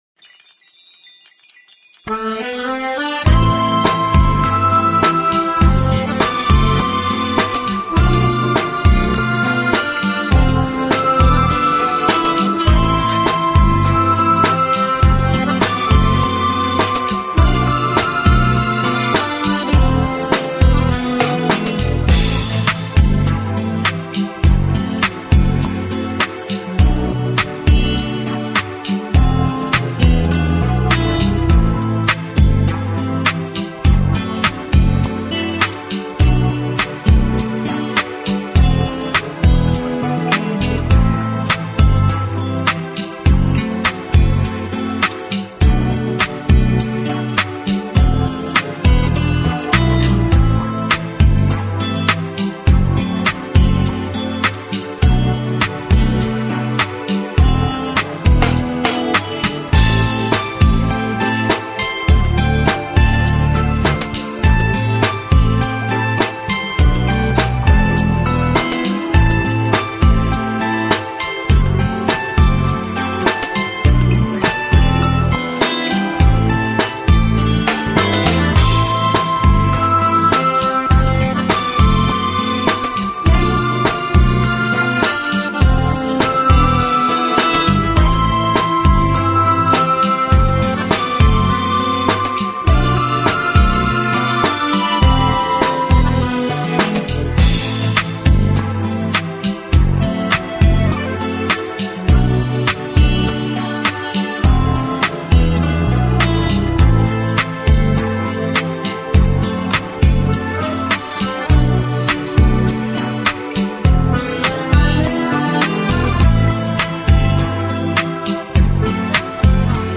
Качество:Ориг